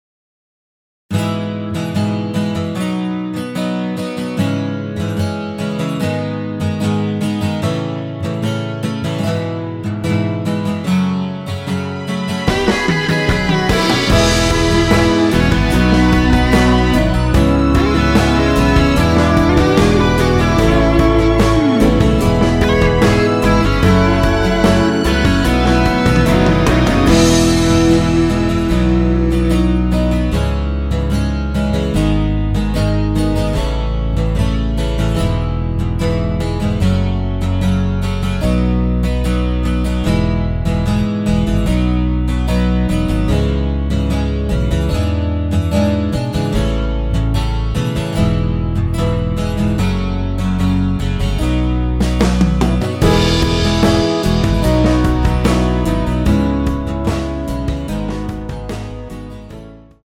원키에서(-3)내린 MR입니다.
앞부분30초, 뒷부분30초씩 편집해서 올려 드리고 있습니다.
중간에 음이 끈어지고 다시 나오는 이유는